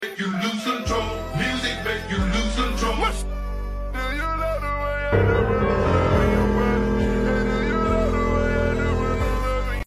Hmm sound effects free download